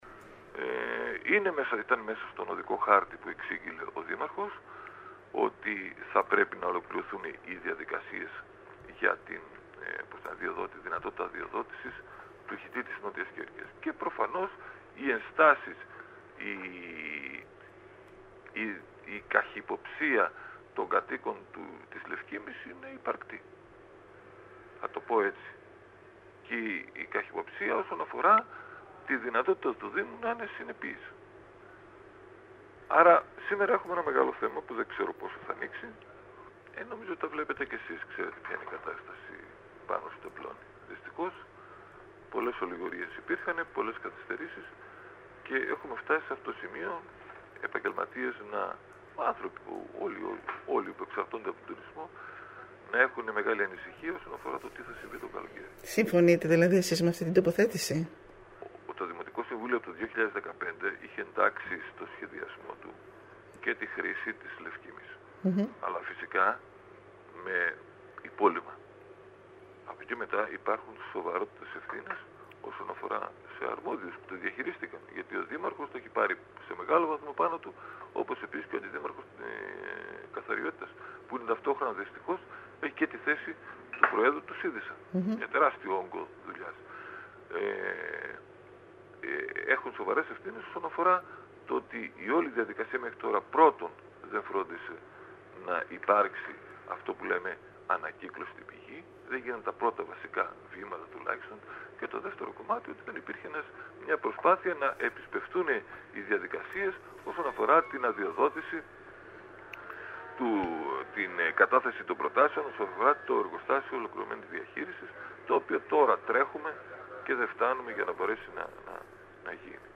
Μιλώντας στην ΕΡΤ Κέρκυρας ο δημοτικός σύμβουλος Γ.  Ραιδεστινός επεσήμανε ότι ο δήμος καθυστέρησε αρκετά, όσον αφορά τις διαδικασίες για την ολοκληρωμένη διαχείριση και χαρακτήρισε δικαιολογημένες τις ενστάσεις των κατοίκων της Λευκίμμης. Ξεκαθάρισε όμως ότι θα πρέπει να εφαρμοστεί το τοπικό σχέδιο που έχει ψηφίσει το δημοτικό συμβούλιο το 2015